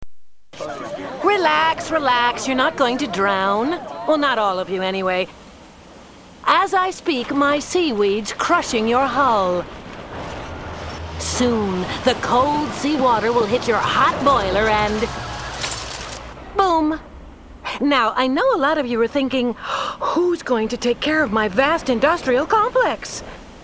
Voix originales